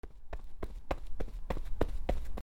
/ I｜フォーリー(足音) / I-180 ｜足音 畳
走る・畳・近付く 01